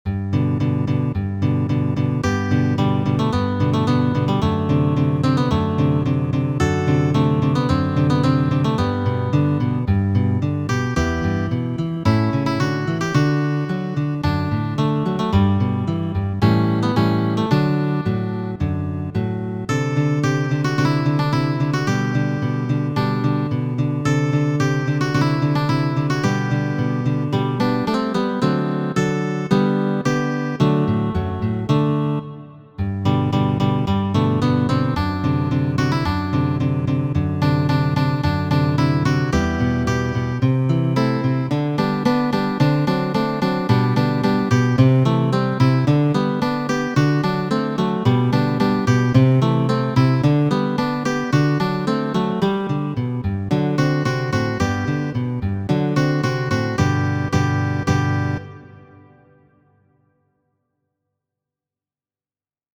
La lanterno, verko por du giraroj de italo Mateo Karkasi, je mia versio.